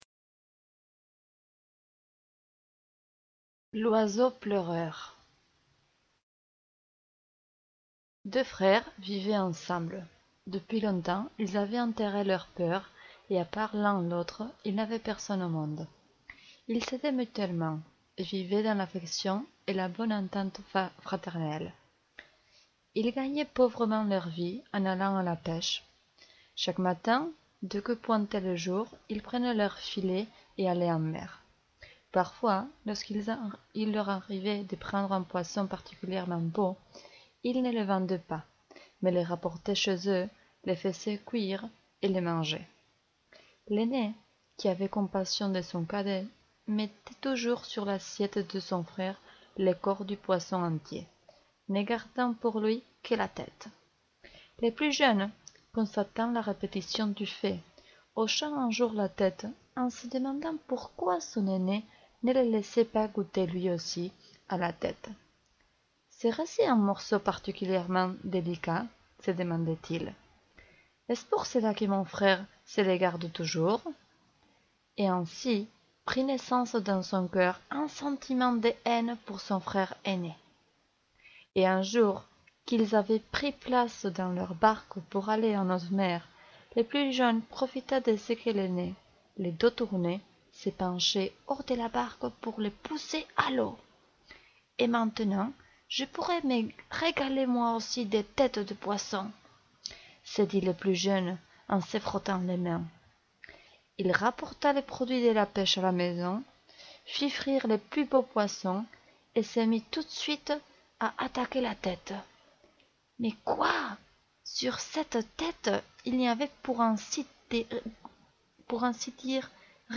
conte